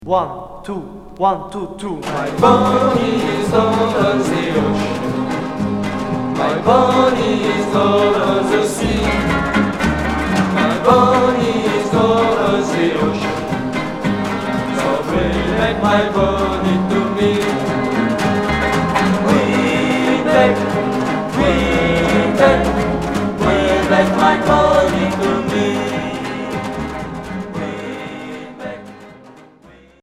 Rock 60's